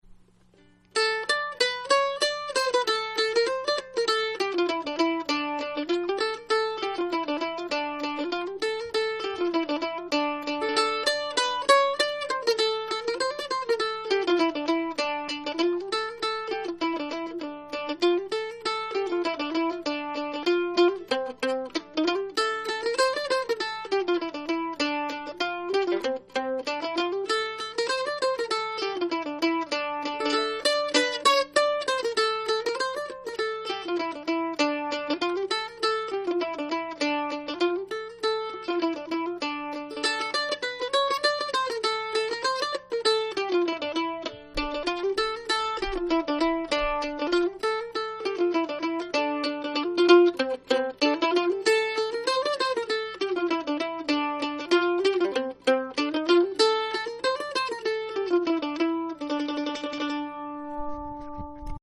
Morris & Country Dance